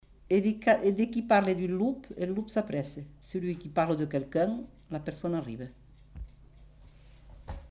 Aire culturelle : Comminges
Effectif : 1
Type de voix : voix de femme
Production du son : récité
Classification : proverbe-dicton